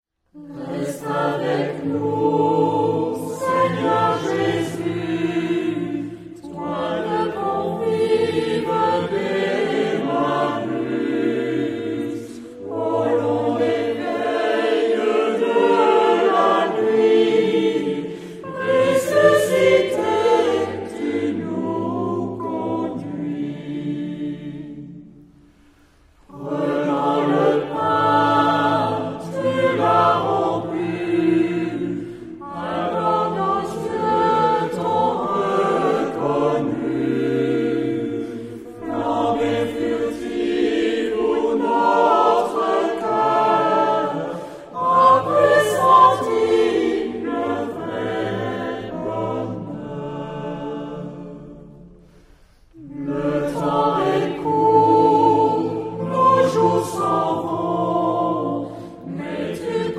Genre-Stil-Form: Hymnus (geistlich) ; geistlich
Charakter des Stückes: klar ; ruhig
Chorgattung: SATB  (4 gemischter Chor Stimmen )
Tonart(en): d-moll